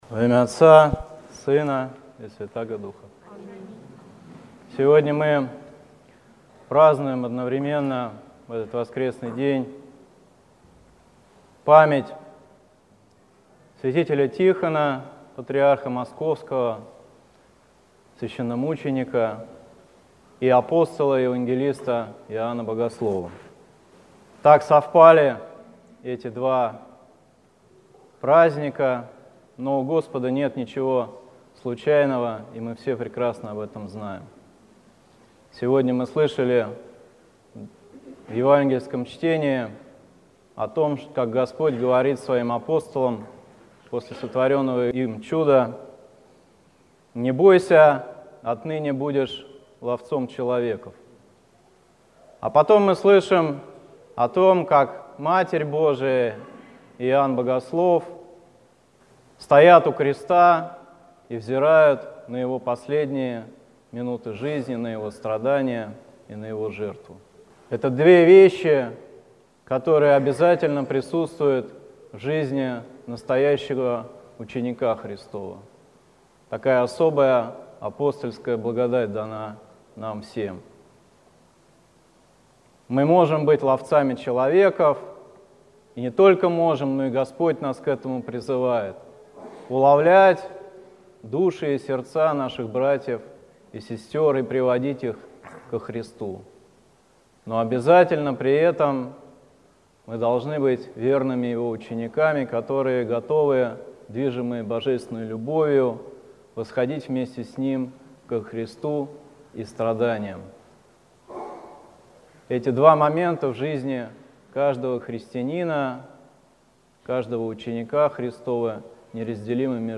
Слово в день памяти апостола и евангелиста Иоанна Богослова и свт. Тихона, патриарха Московского и всея Руси